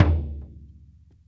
SingleHit_QAS10775.WAV